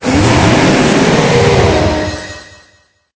Cri d'Éthernatos dans Pokémon Épée et Bouclier.